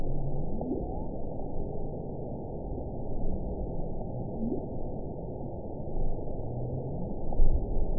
event 919454 date 01/04/24 time 08:13:51 GMT (1 year, 4 months ago) score 8.53 location TSS-AB07 detected by nrw target species NRW annotations +NRW Spectrogram: Frequency (kHz) vs. Time (s) audio not available .wav